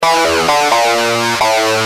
Lead_b1.wav